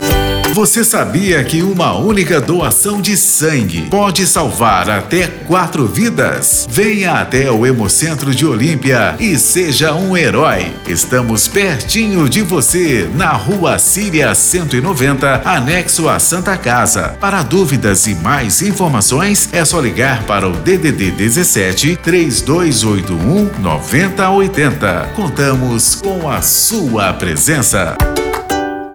Você também pode ajudar a divulgar a doação de sangue usando nossos spots para rádio ou carros de som: